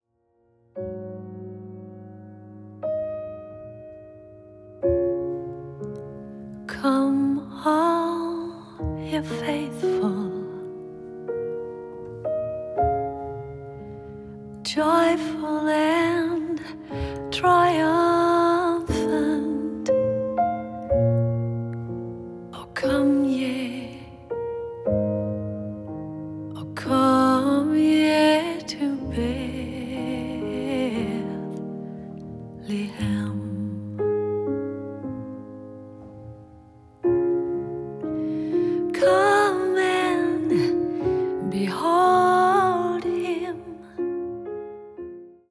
録音：2014年 ミュンヘン